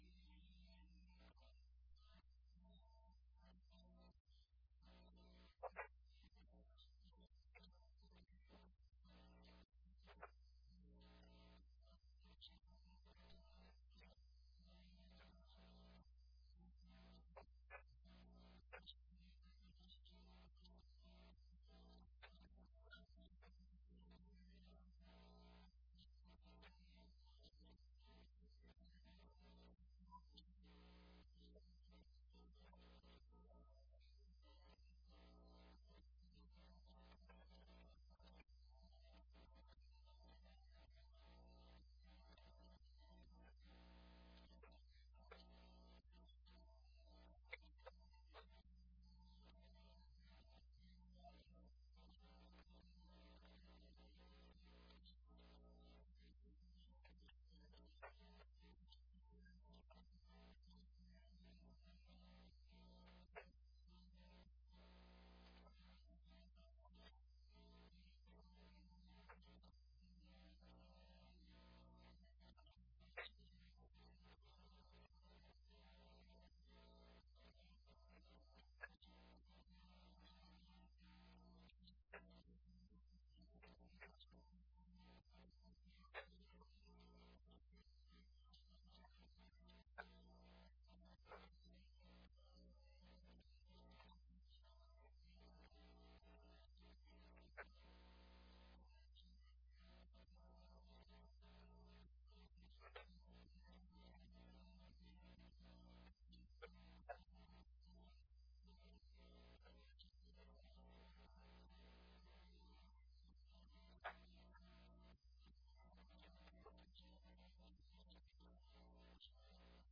Audio only link (mp3) – Worship starts @18:00; Sermon @48:10